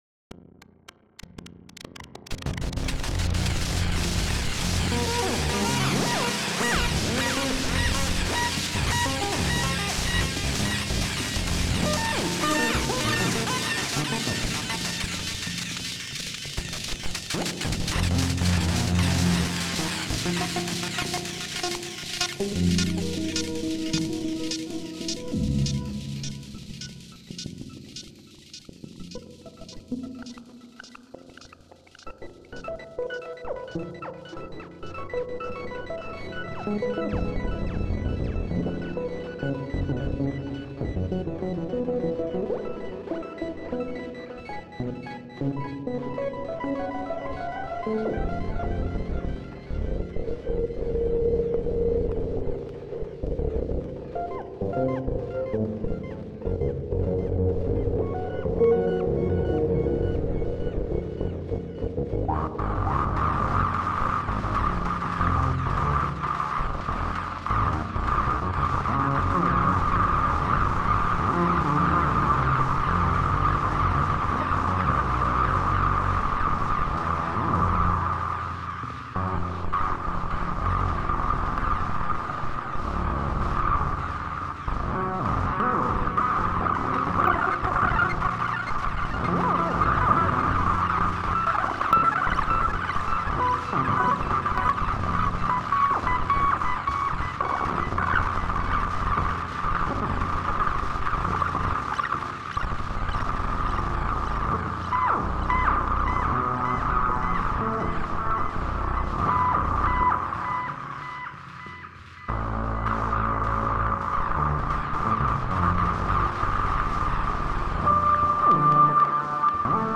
Atmósfera sintética futurista
Grabación de sonido sintético creado para simular una atmósfera futurista
envolvente atmósfera electrónica música sintético sintetizador sonido